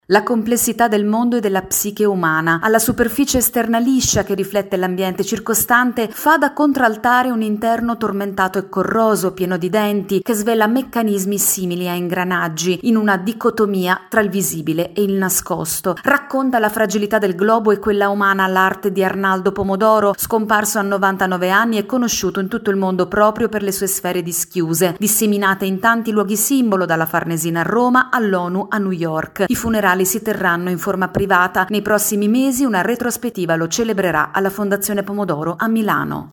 Addio ad Arnaldo Pomodoro, scultore conosciuto in tutto il mondo specie per le sue “sfere” dischiuse. Il servizio